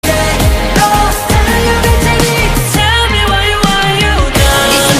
OLIVER_90_drum_fill_tom_wide_wet